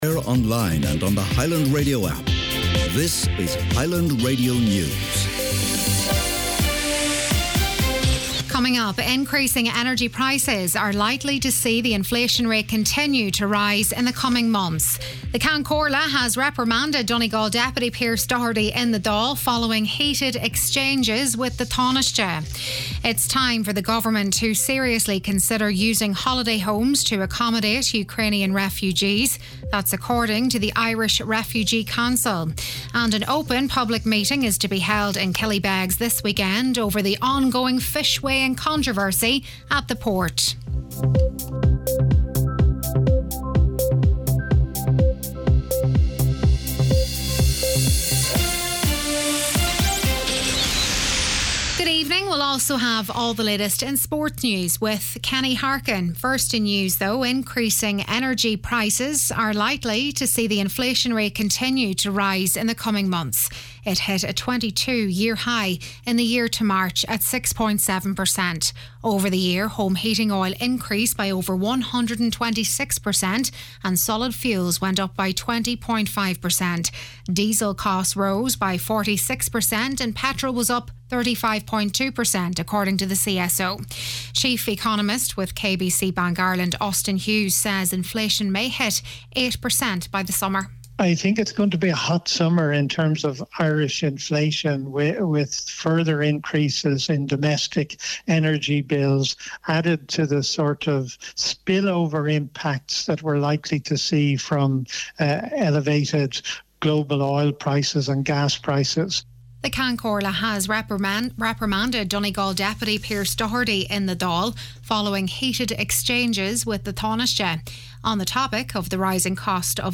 Listen back to main evening news, sport, farming & obituaries